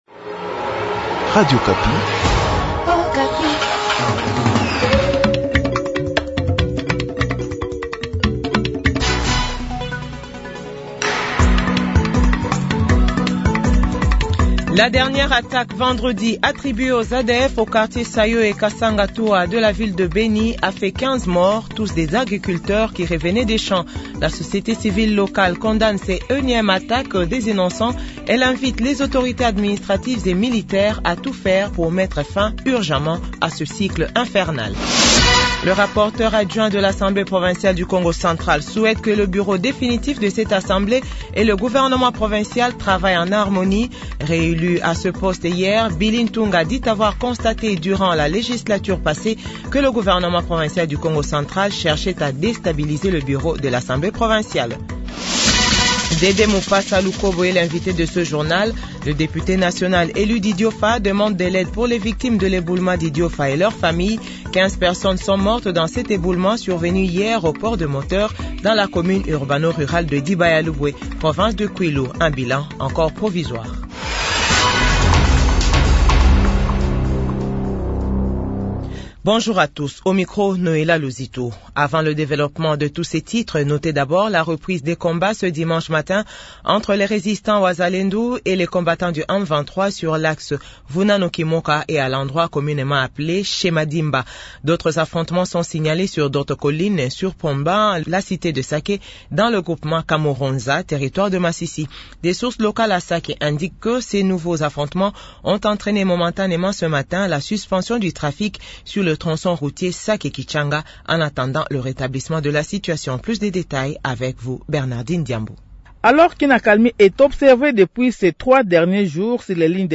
JOURNAL FRANCAIS 12H00